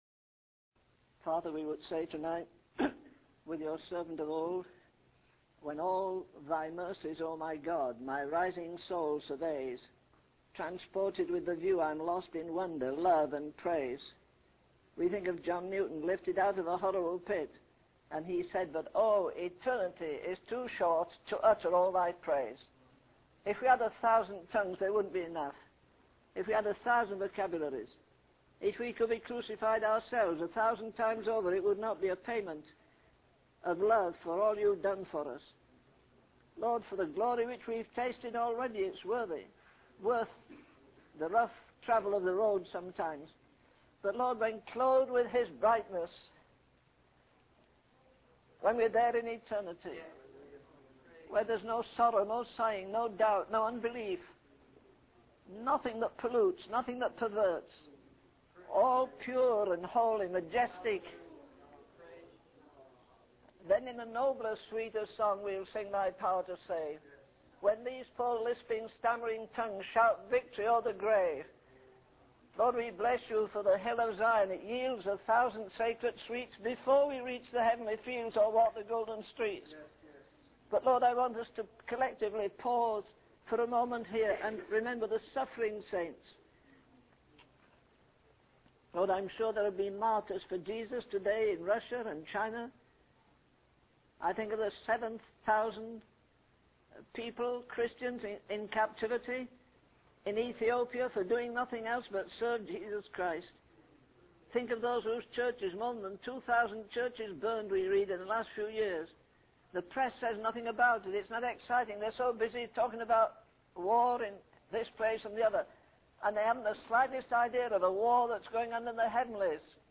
In this sermon, the preacher emphasizes the need to prove the preaching of the word of God through action. He encourages believers to open the door and boldly proclaim the truth to a world that is scared and threatened.